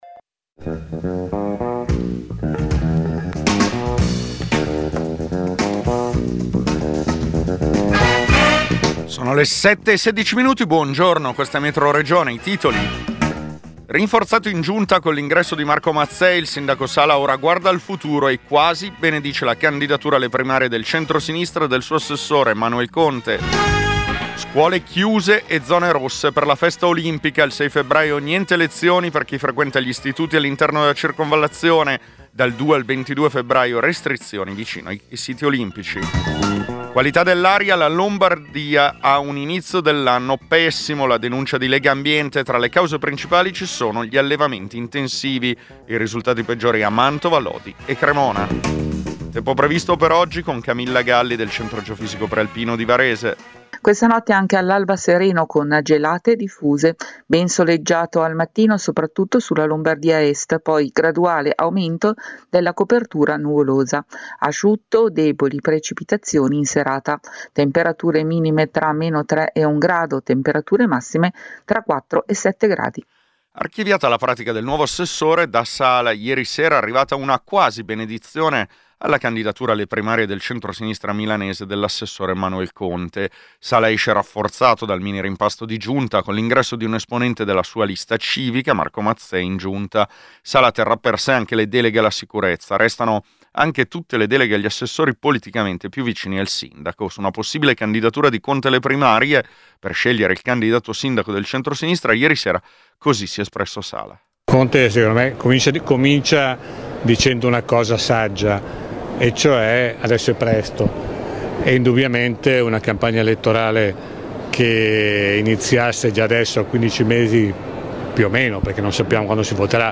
Metroregione è il notiziario regionale di Radio Popolare. Racconta le notizie che arrivano dal territorio della Lombardia, con particolare attenzione ai fatti che riguardano la politica locale, le lotte sindacali e le questioni che riguardano i nuovi cittadini.